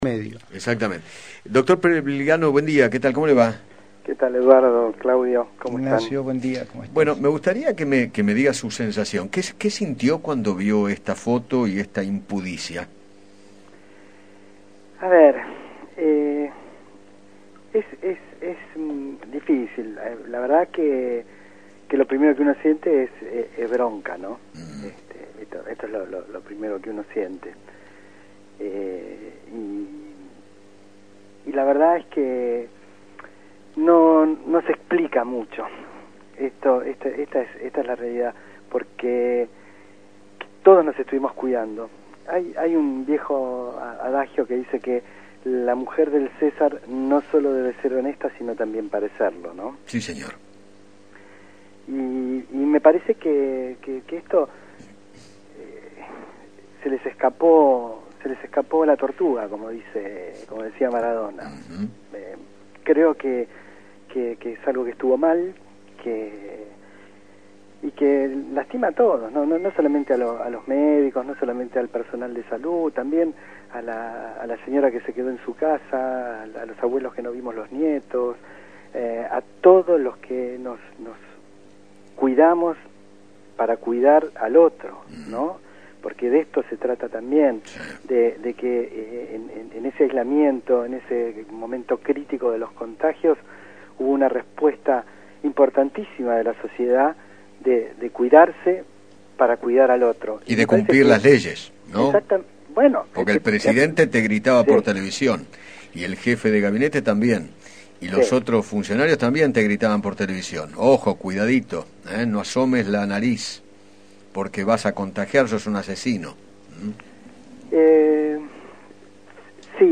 conversó con Eduardo Feinmann sobre la polémica foto del festejo de cumpleaños de Fabiola Yáñez